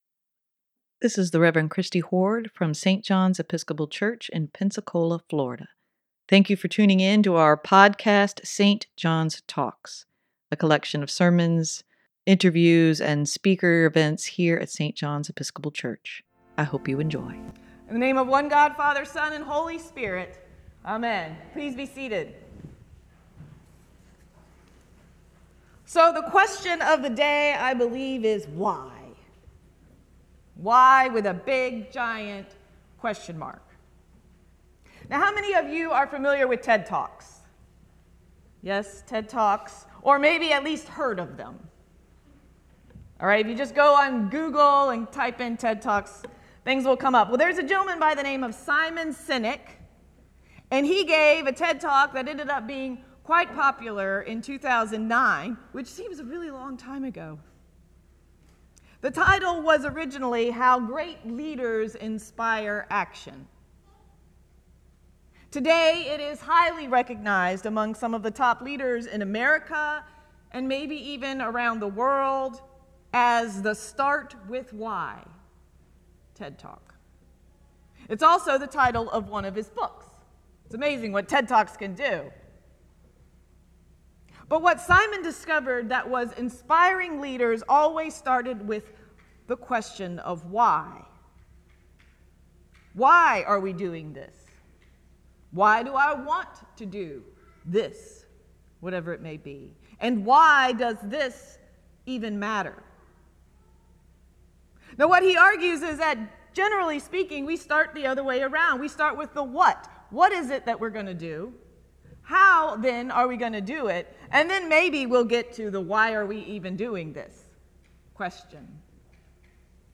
Sermon for Sunday, July 17, 2022: Start With the “Why”